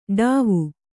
♪ d`āvu